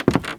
STEPS Wood, Creaky, Walk 16.wav